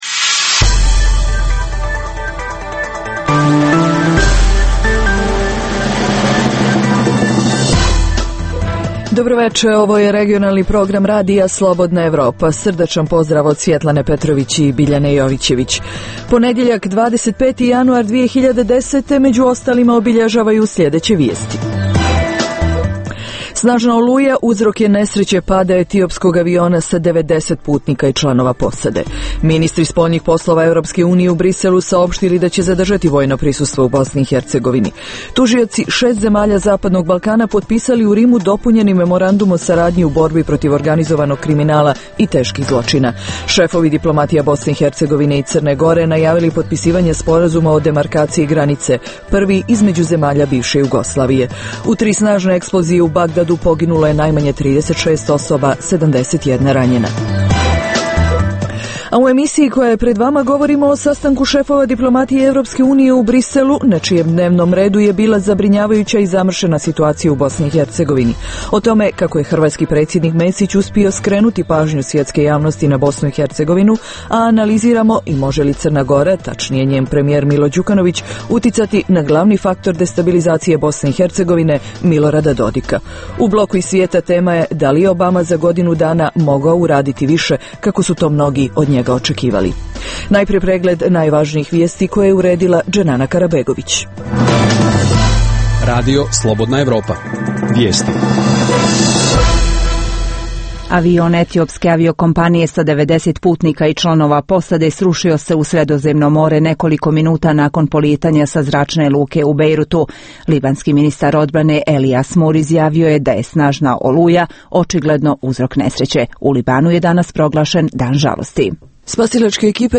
U bloku iz svijeta tema je da li je Obama za godinu dana mogao uraditi više, kako su to mnogi od njega očekivali U Dokumentima dana za RFE govori ministar sigurnosti BiH Sadik Ahmetović